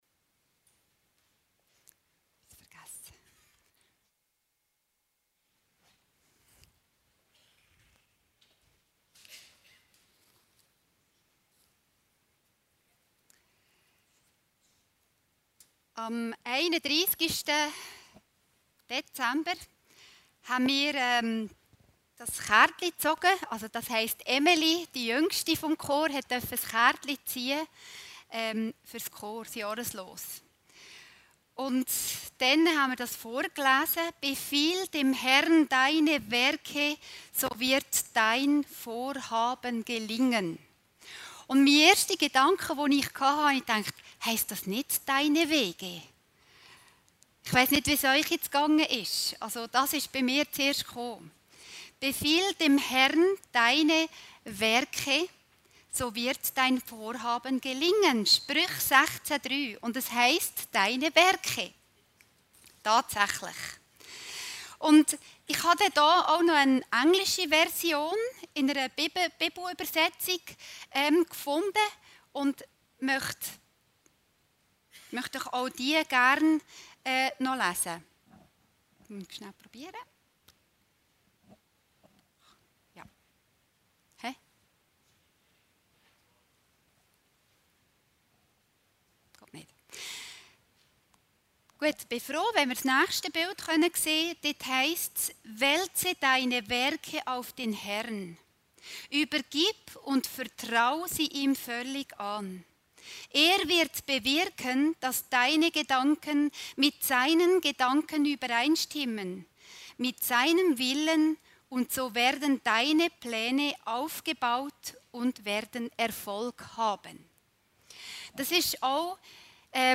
Predigten Heilsarmee Aargau Süd – GOTT UNSERE PLÄNE ANVERTRAUEN